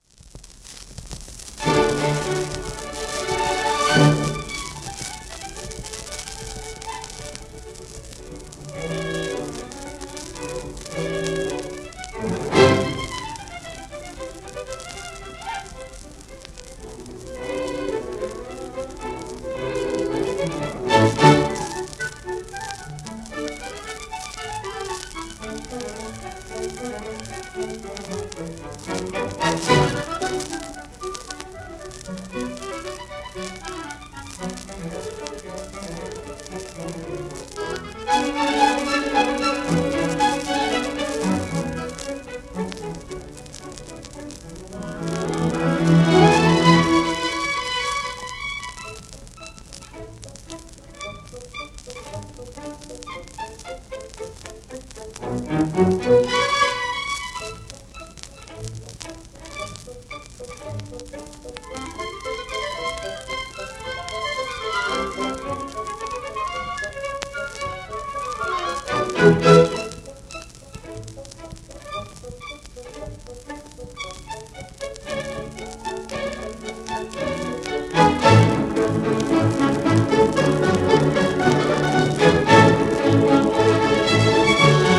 盤質B+ *面擦れ,キズ,導入クリック音あり
1944年頃録音、柔らかい材質です。